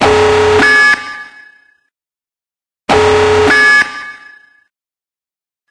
striderSiren.ogg